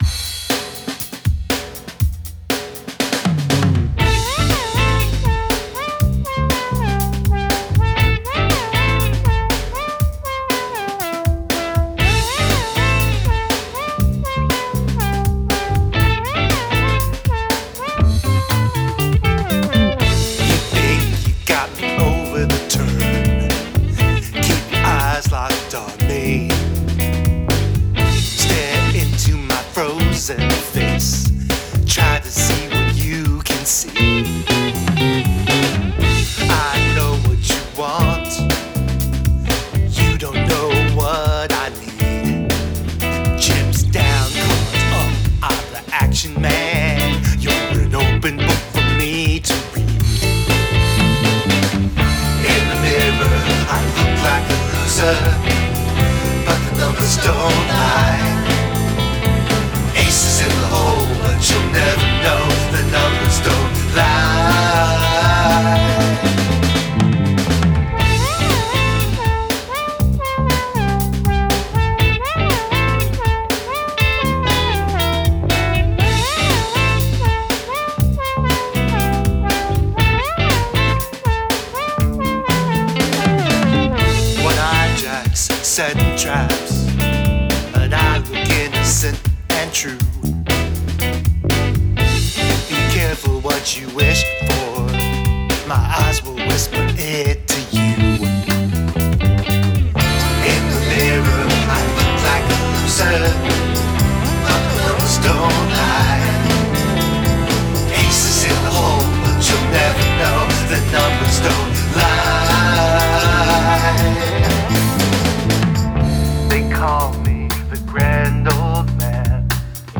Use the Royal Road chord progression
Outro repeats the F G Em Am